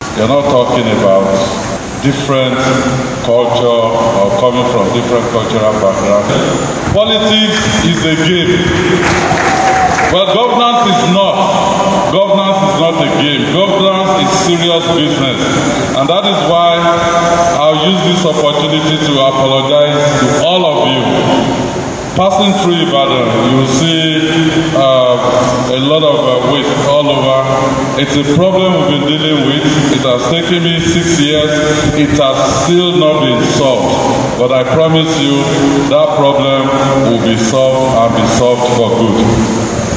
The governor gave the assurance while speaking at an event in Ibadan, the Oyo State capital, where he acknowledged the persistent nature of the waste disposal challenges confronting the city.